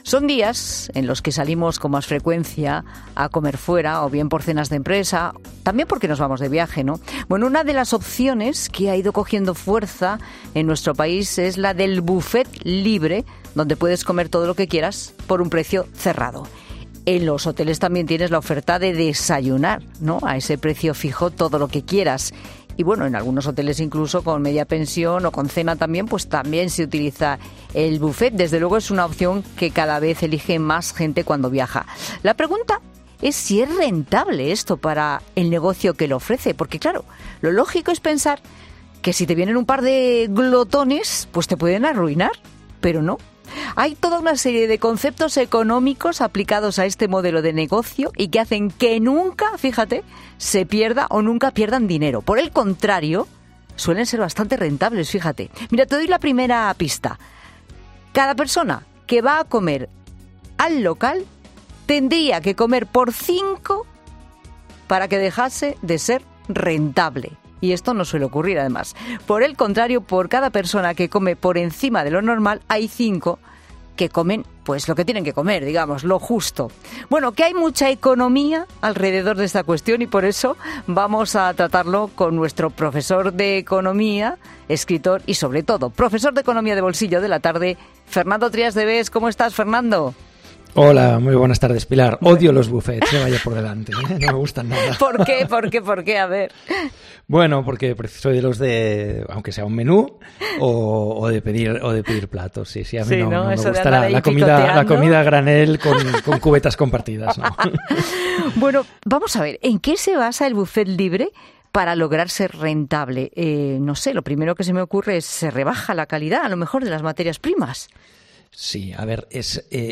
Hay mucha economía alrededor de esta cuestión y Fernando Trías de Bes, economista y escritor, lo analiza en 'La Tarde'.